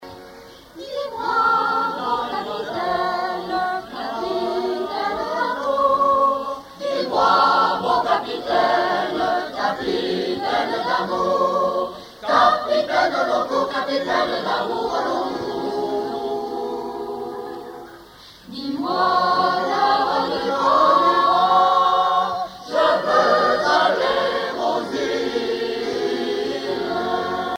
Chorale
Chansons de la soirée douarneniste 88
Pièce musicale inédite